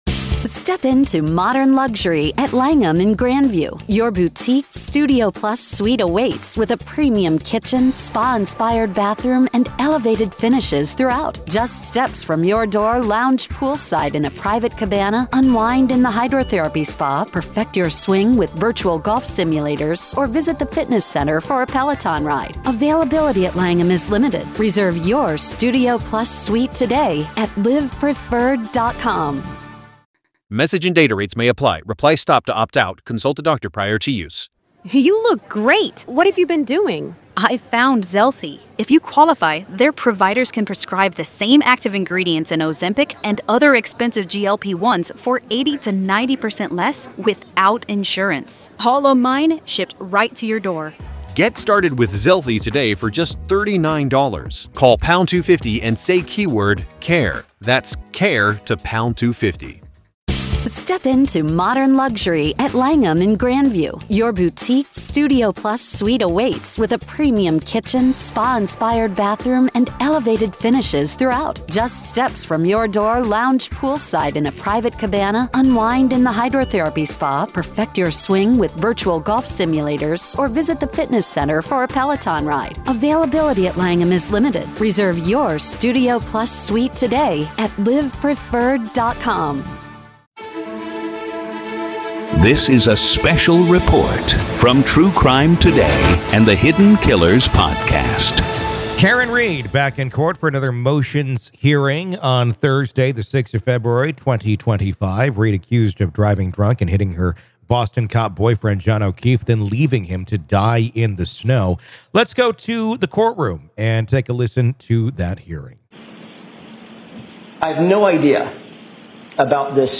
RAW COURT AUDIO: PART 2 | Karen Read Hearing Update: Defense Wins Key Motion as Trial Approaches